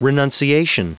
Prononciation du mot renunciation en anglais (fichier audio)
renunciation.wav